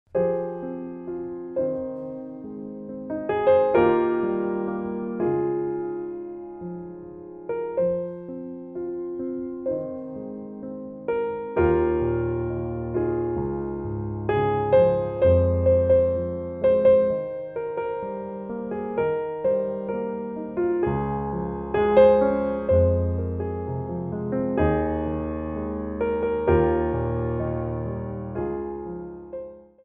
Piano Arrangements of Pop & Rock for Ballet Class
4/4 (16x8)